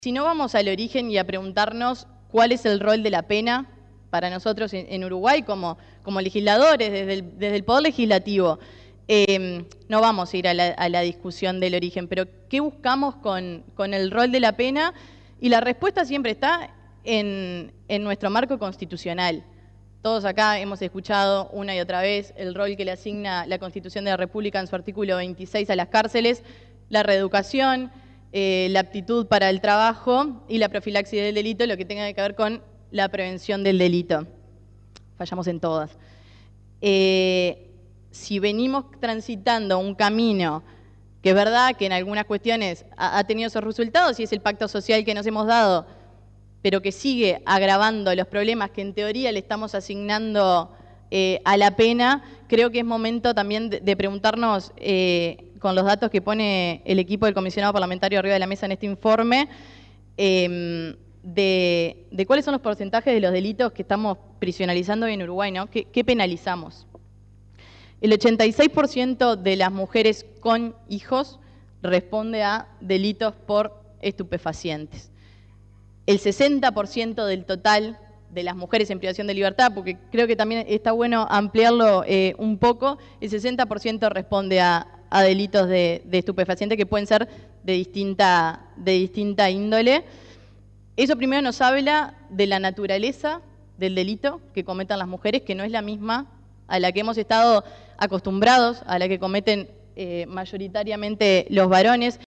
En la presentación de este informe, hicieron uso de la palabra diferentes actores políticos, tanto del gobierno como legisladores del oficialismo y de la oposición.
La diputada del Partido Nacional, Fernanda Sfeir, se refirió al origen de las penas y las altas tasas de mujeres privadas de libertad. En este sentido, señaló que la respuesta al rol de la pena está en nuestro marco constitucional, mencionó lo qué penalizamos y los diferenció por género.